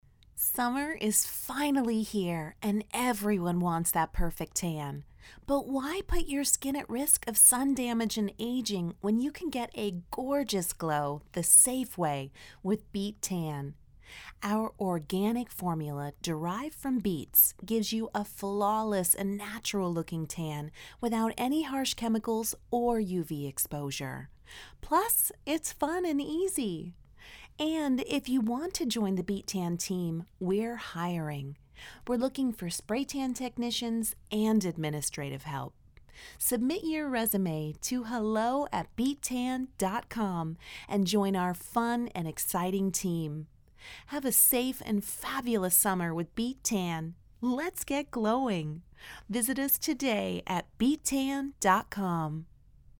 Strong, feminine and warm, professional and prompt
Young Adult
Middle Aged
I use an Audio Technica Cardioid Condenser microphone and Pro Tools Artist for basic recording and editing.